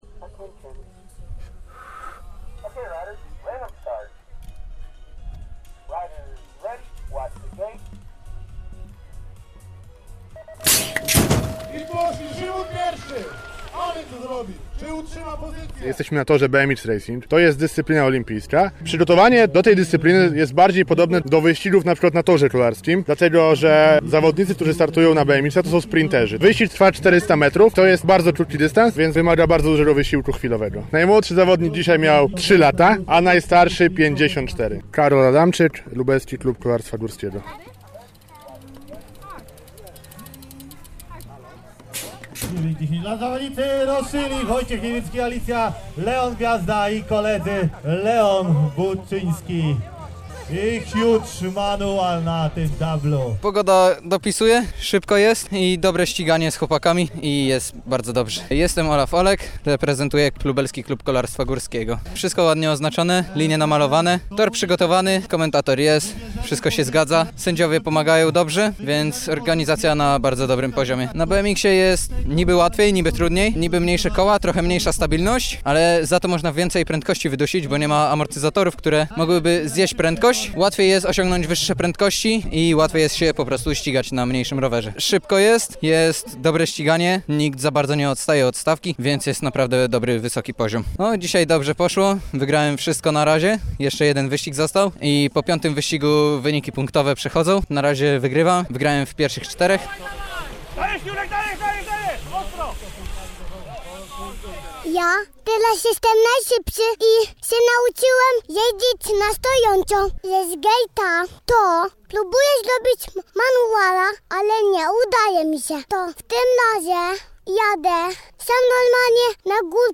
Profesjonalne wyścigi BMX pojawiają się także na Lubelszczyźnie. w ten weekend na torze kolarskim przy ulicy Janowskiej odbyły się pierwsze zawody z cyklu Lubelska Liga BMX Racing.